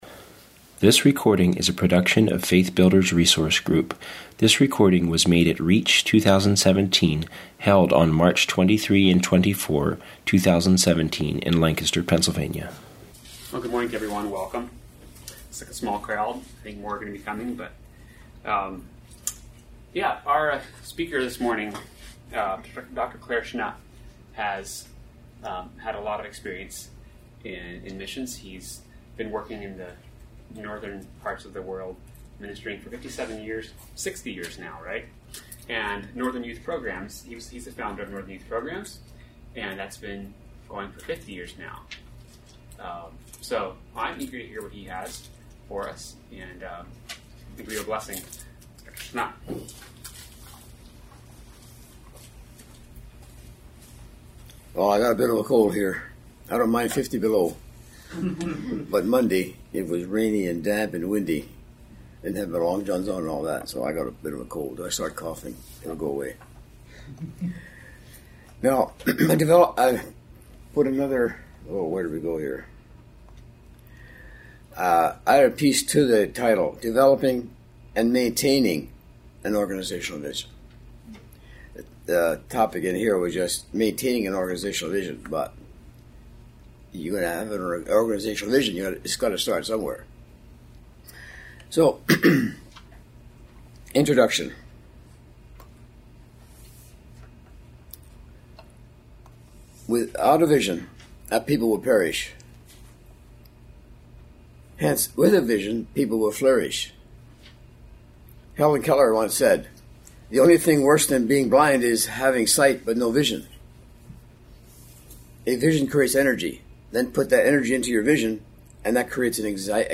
Home » Lectures » Maintaining an Organizational Vision